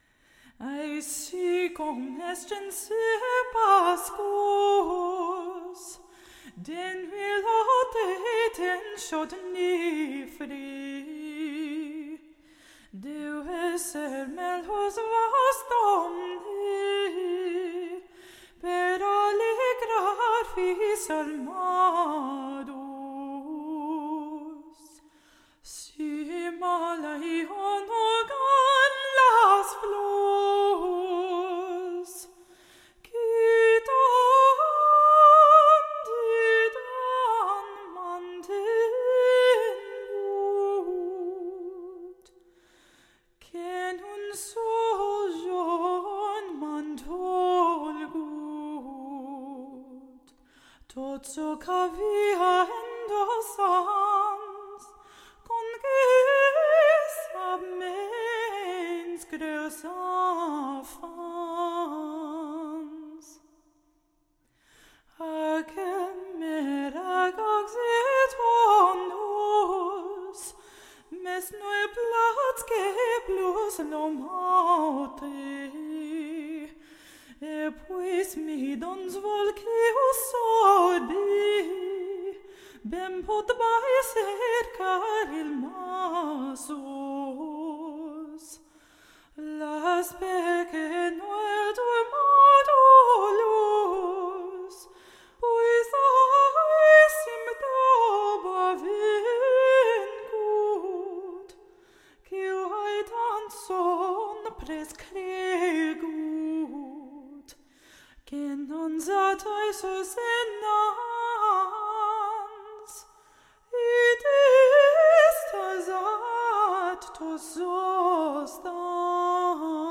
female vocalist, free rhythm
same melody each time, single vocalist